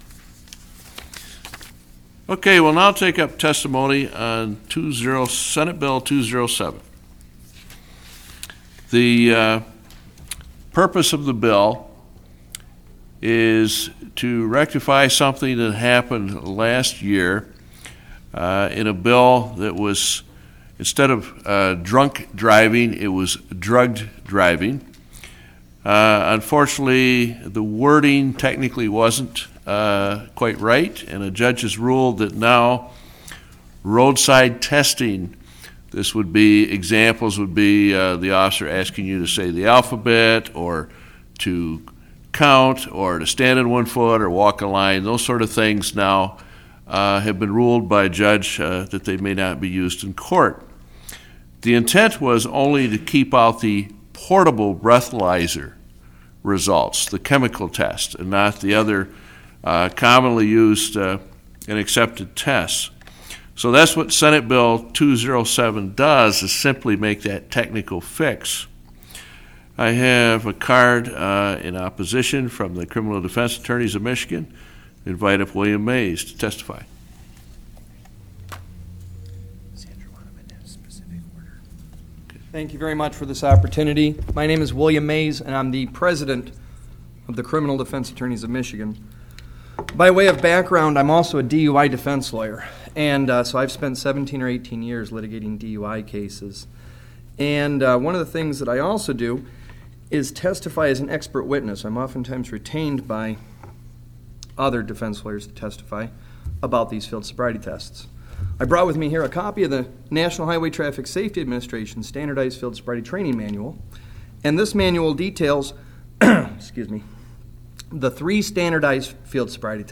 Listen to my testimony before the Senate Judiciary Committee on Field Sobriety Tests in Michigan!
michigan-field-sobriety-tests-testimony.mp3